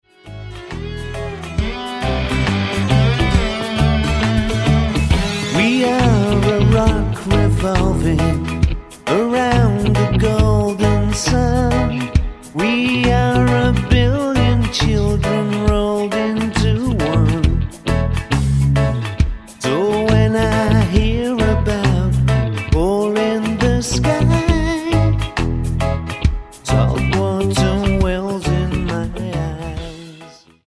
Alternative,Indigenous,New Age,Soundtrack